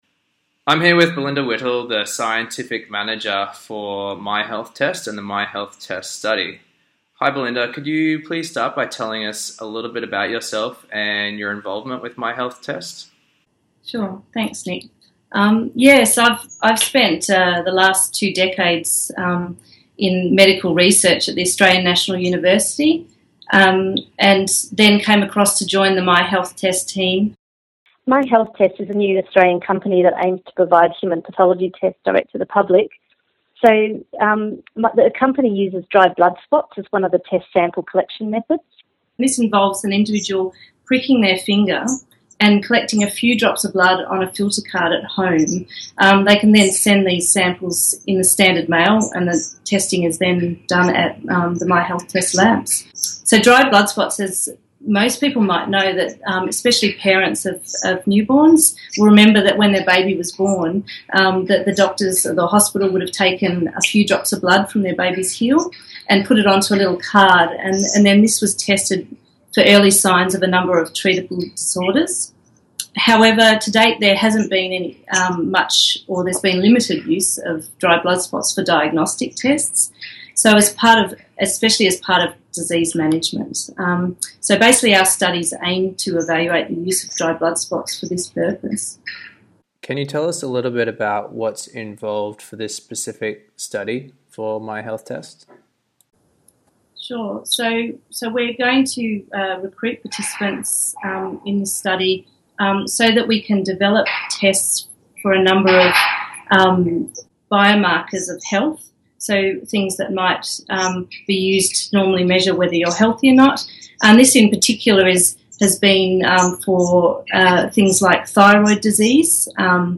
Researcher Interview